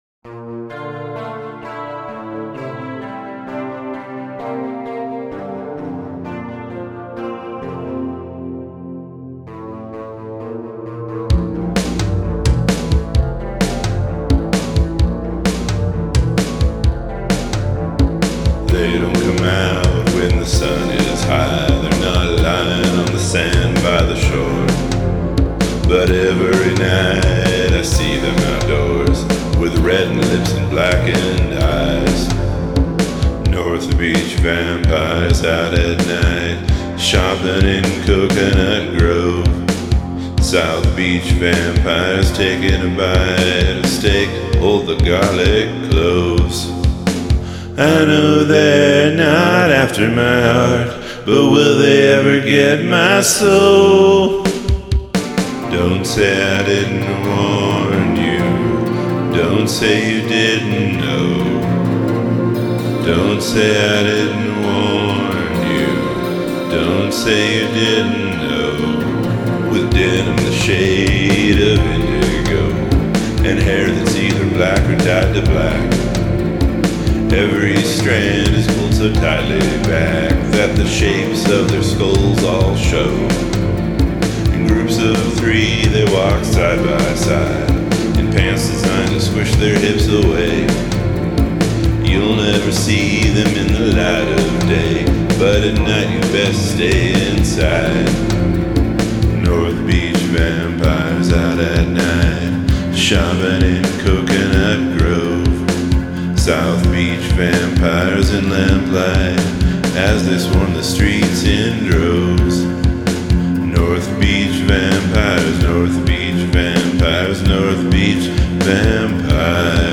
Include an a cappella section